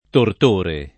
vai all'elenco alfabetico delle voci ingrandisci il carattere 100% rimpicciolisci il carattere stampa invia tramite posta elettronica codividi su Facebook tortore [ tort 1 re ] s. m. — voce ant. per «carnefice», region. per «bastone»